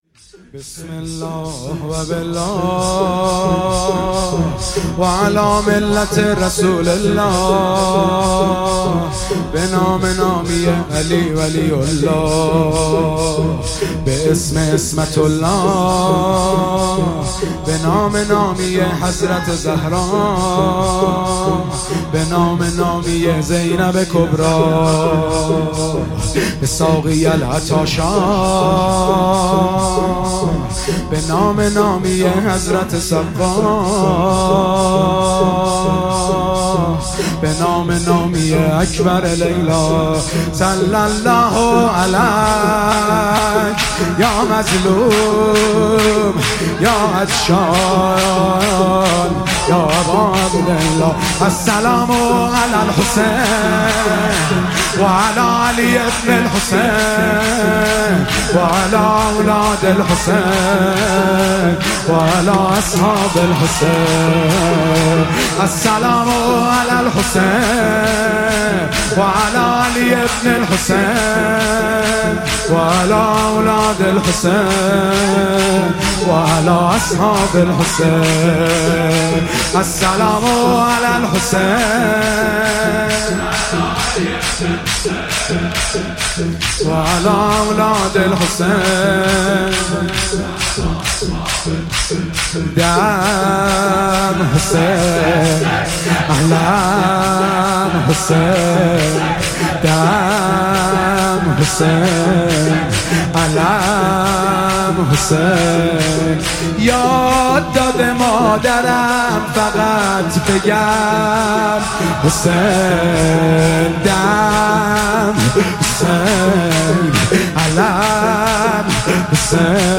محرم98 - شور - بسم الله و بالله و علی...
شب اول محرم